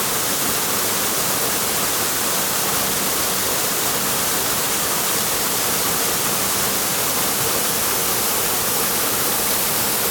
458清流,渓流,滝,水音,谷川,小川,
効果音自然野外